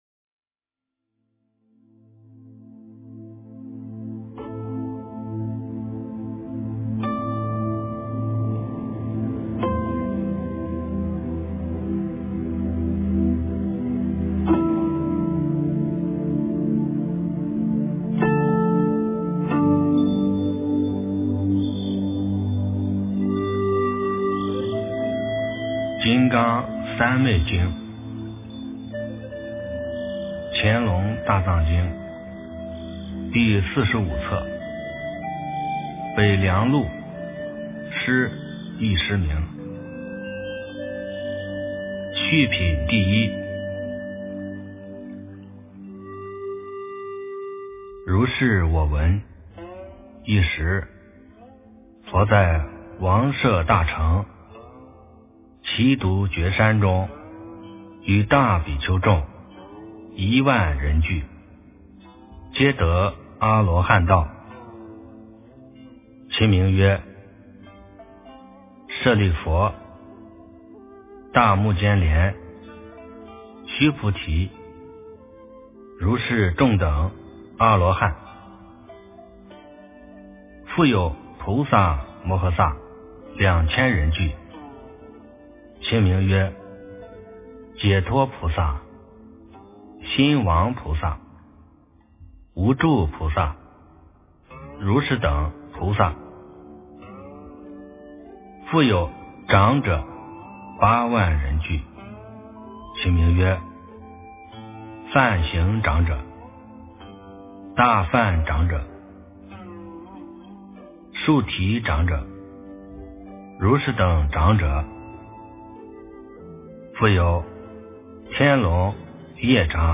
金刚三昧经 - 诵经 - 云佛论坛